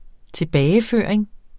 Udtale [ -ˌføˀɐ̯eŋ ]